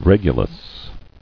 [Reg·u·lus]